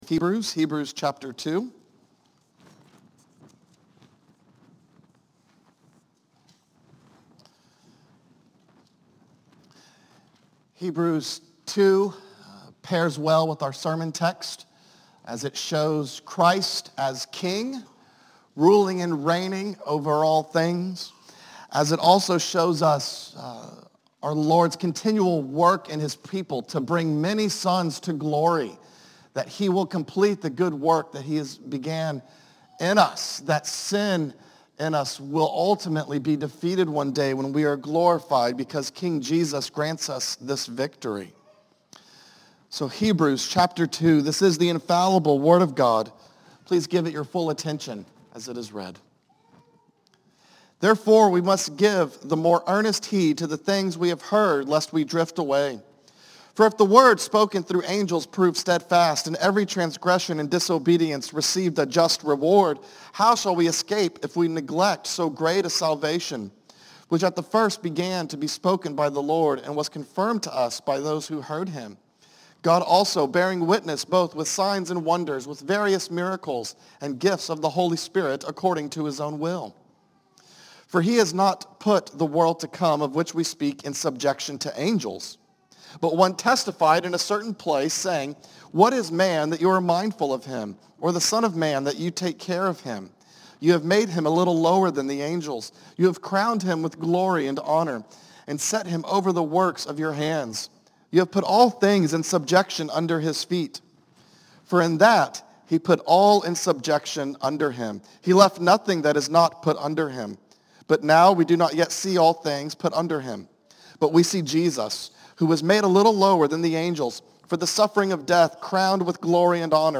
A message from the series "Psalms."